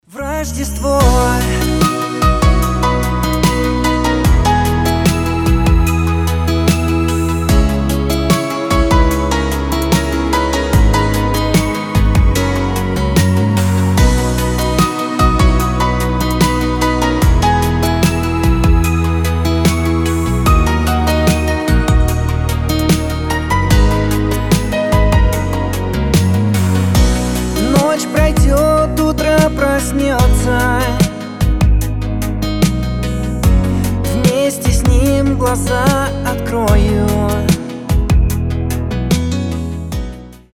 • Качество: 320, Stereo
мелодичные
спокойные
рождественские
Красивая рождественская композиция